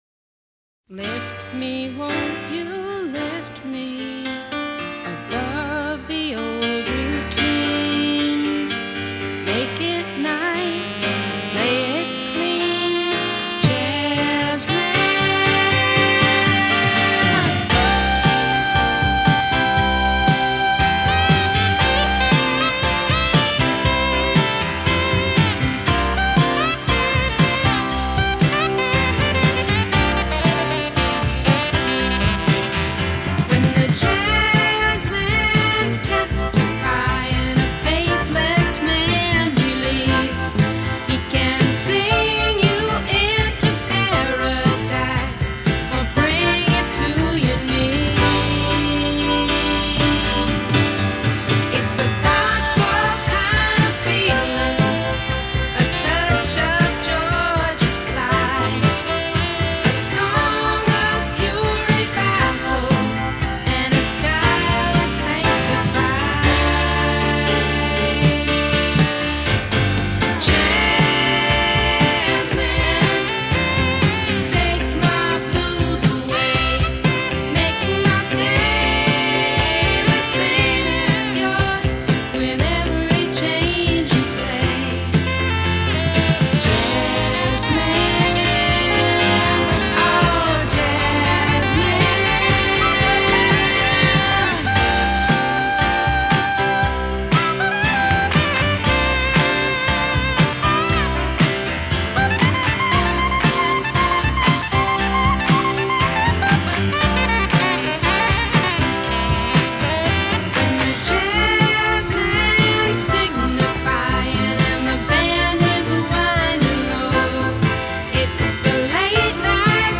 Pop, Folk Rock